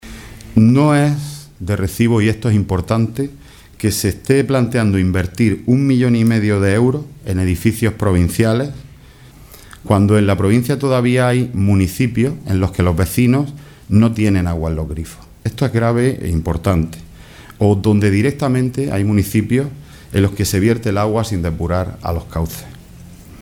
Rueda de prensa que ha ofrecido el Grupo Socialista de la Diputación Provincial